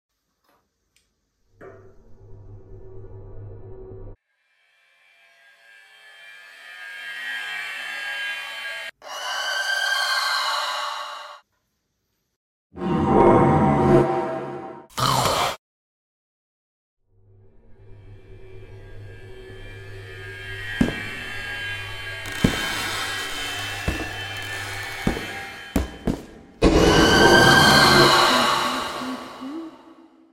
Sounds of Poppy Playtime chapter sound effects free download
Sounds of Poppy Playtime chapter 3 in real life!